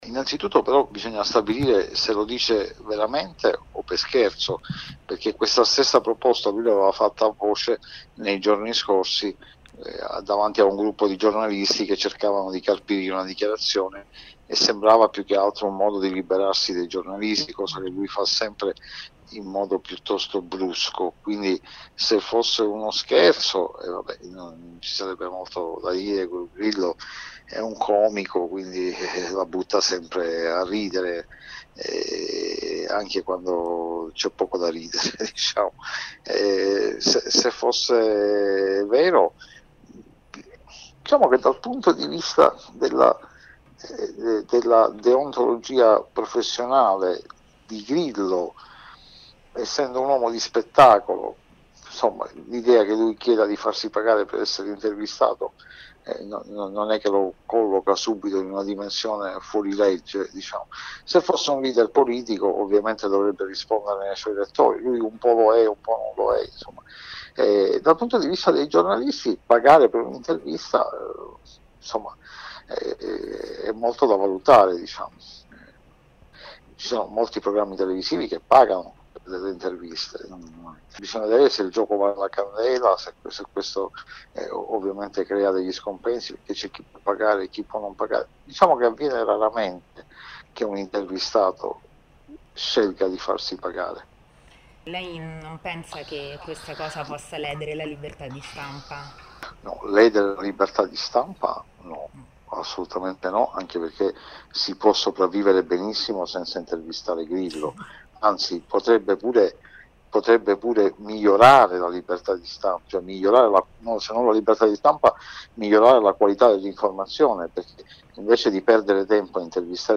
Con queste parole l’editorialista de La Stampa, Marcello Sorgi, in un’intervista rilasciata a Lumsanews, commenta l’ultima idea del fondatore del Movimento 5 stelle: creare un tariffario per le interviste a giornali e televisioni.